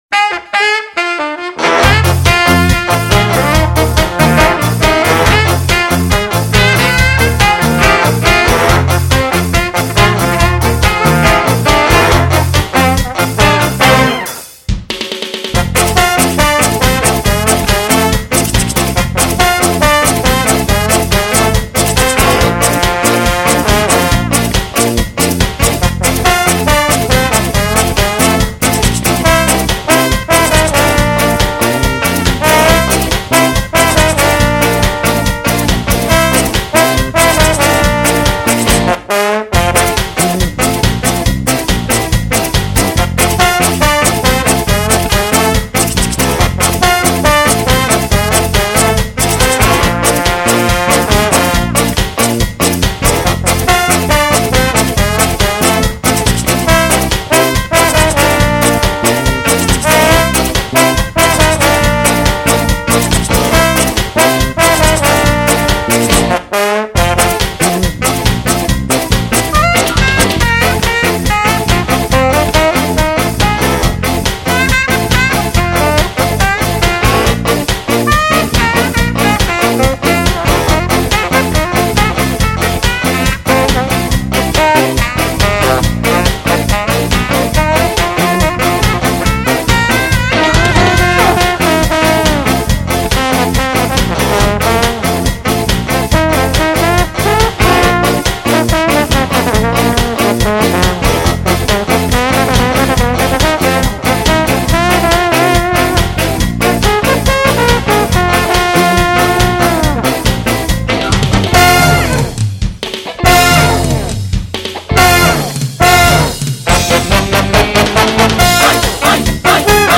제가 지금껏 올려드린 곡들 중가장 우스꽝스러운 슬로우 풍의 스카 곡이 아닐까 합니다.